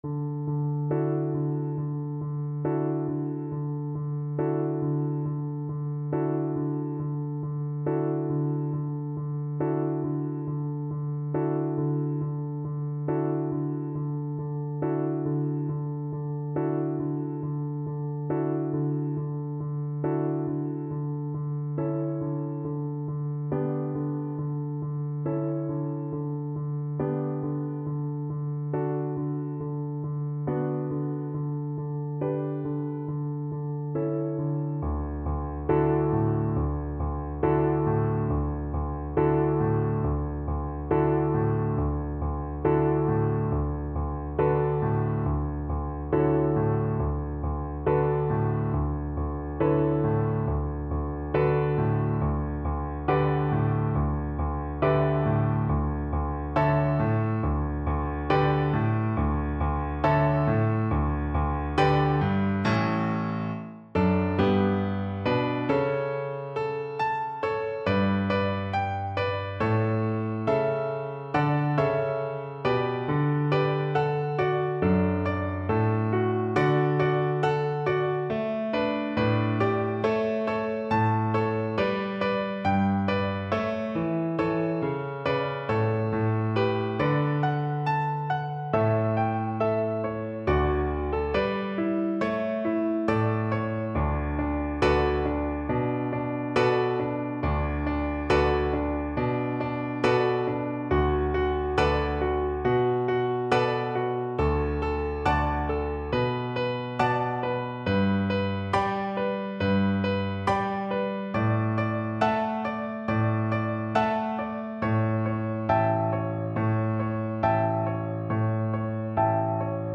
Play (or use space bar on your keyboard) Pause Music Playalong - Piano Accompaniment Playalong Band Accompaniment not yet available transpose reset tempo print settings full screen
Violin
D major (Sounding Pitch) (View more D major Music for Violin )
Moderato =c.100
4/4 (View more 4/4 Music)
Traditional (View more Traditional Violin Music)